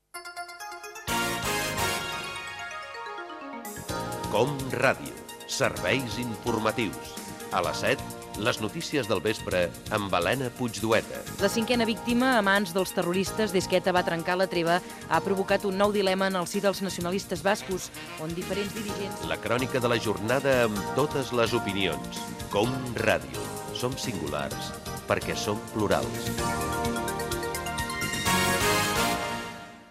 Promoció del programa
Informatiu